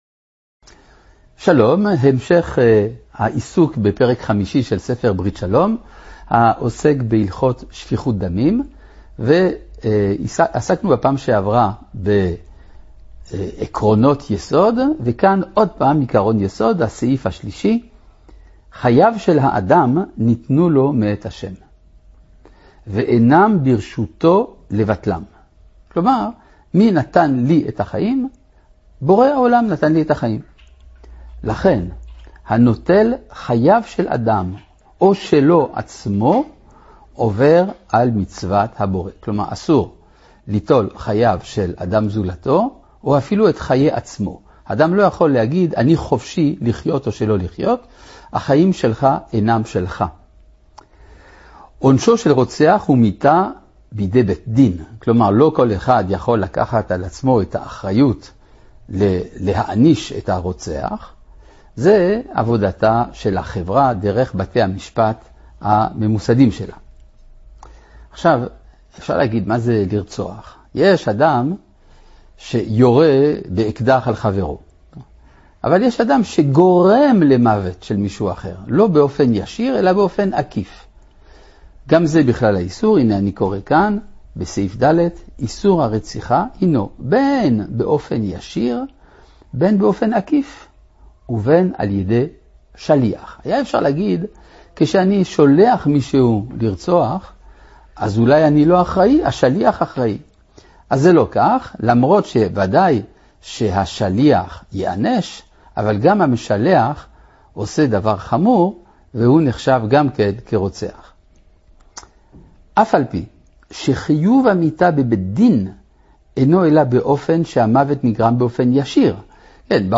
שיעור 41 - חלק ה', פרק 1, 3 דקות (MP3, 2MB).